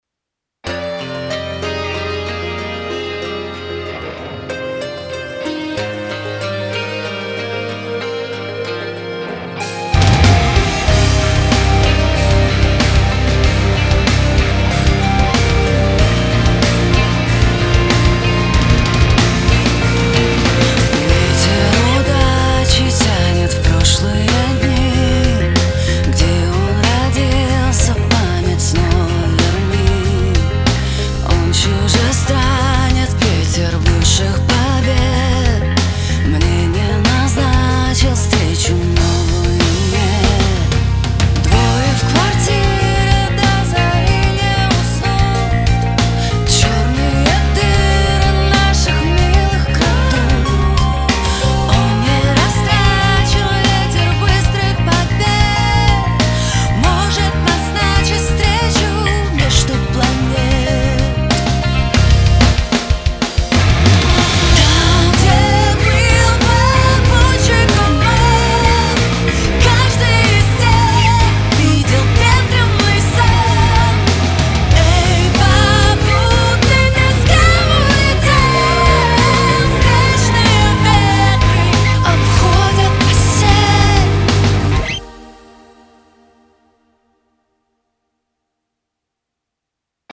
Попутчик. Alternative Rock. Demo.
Песня пока в процессе работы, не до конца.